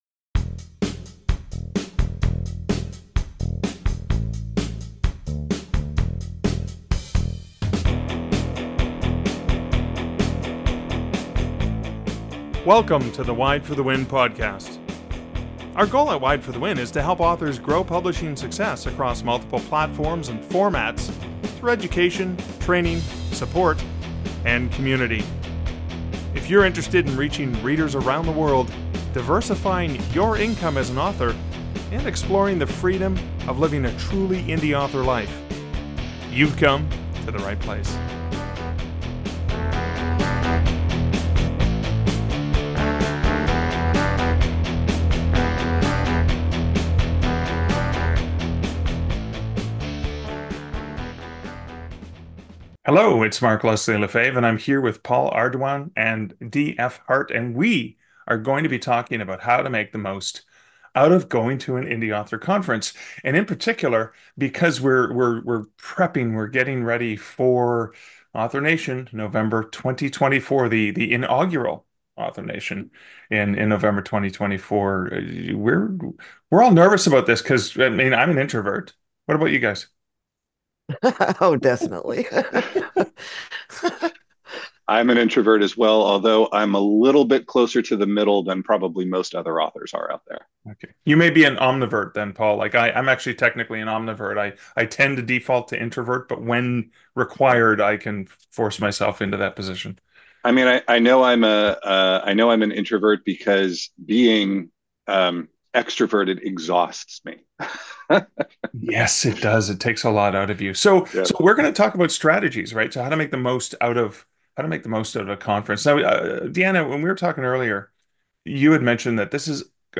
So what are some of the strategies you can use in planning ahead to make the most of your investment? Several WFTW board members use the forthcoming November 2024 Author Nation conference as an example for some of the strategies and planning you can do to set yourself up for in person conference success.